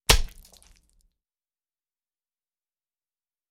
Этот помидор мы швырнули в стену